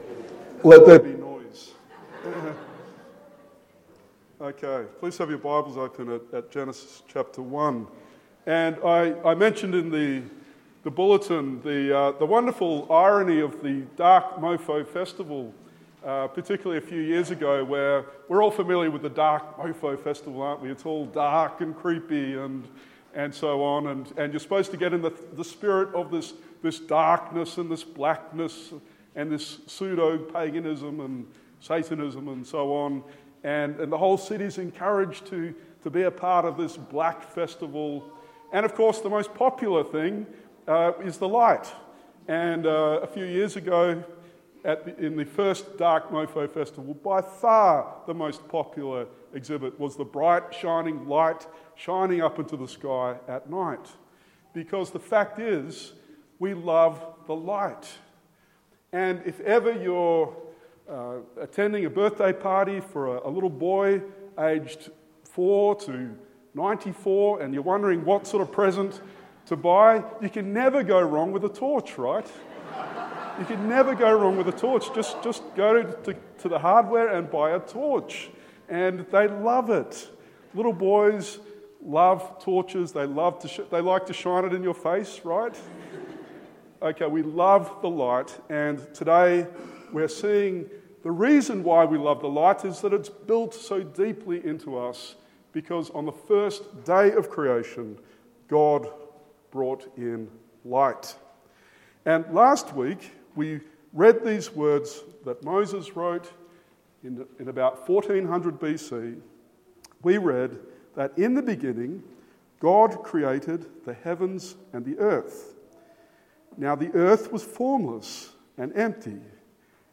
Genesis 1:1-5 Sermon